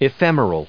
EPHEMERAL /iˈfɛmərəl/